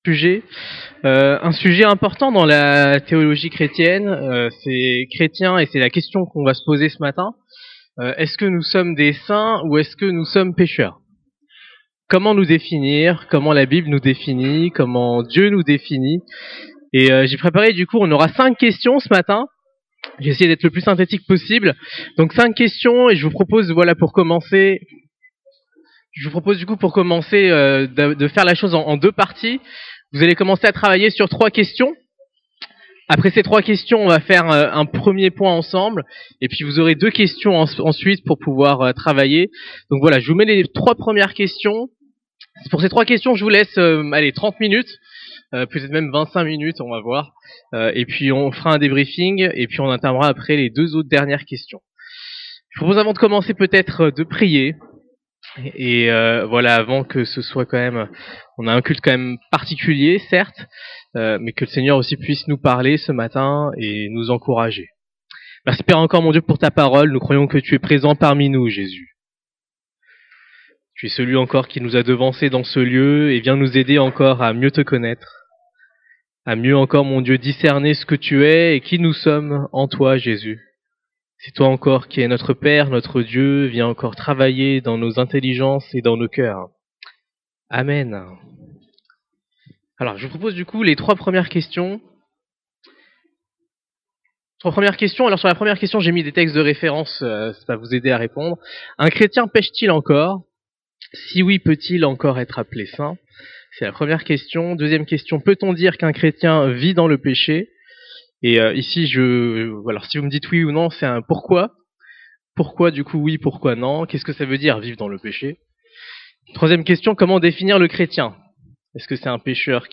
sommes-nous saints ou pécheurs en Christ Prédicateur